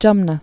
(jŭmnə)